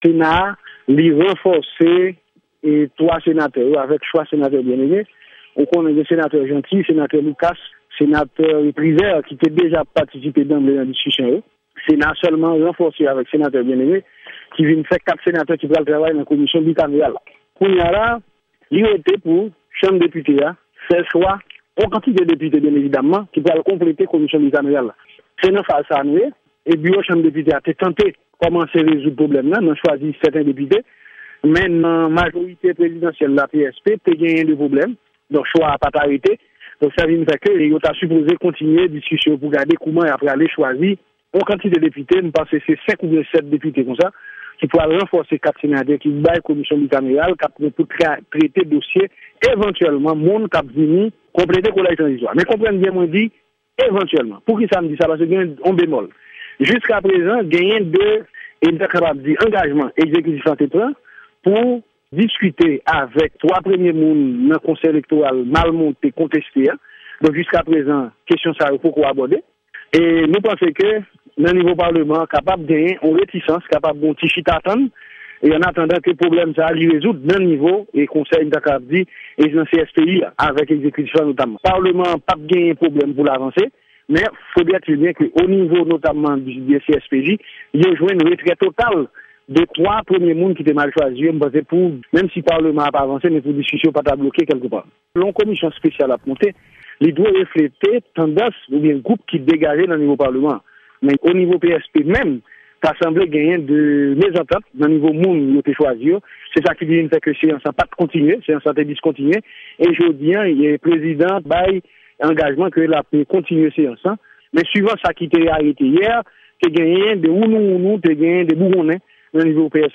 Entèvyou Depite Dérilus